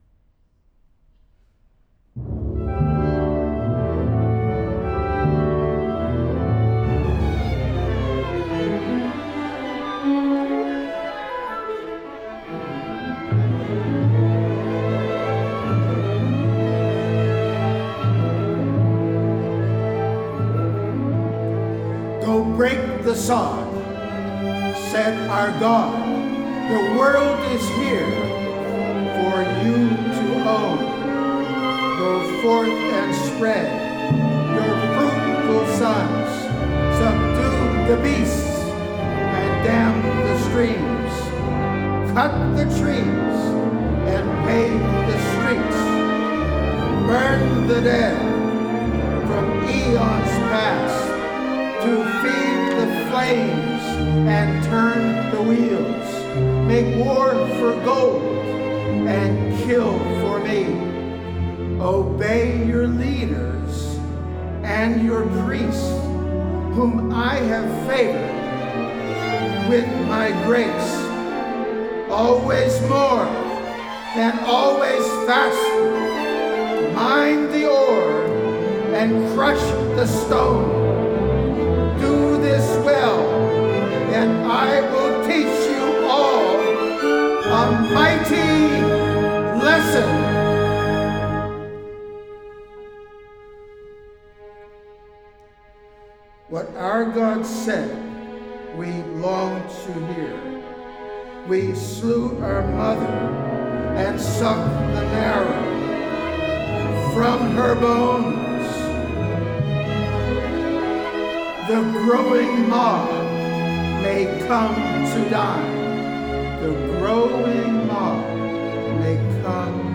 for Narrator and Orchestra
tempo: Allegro apocalypso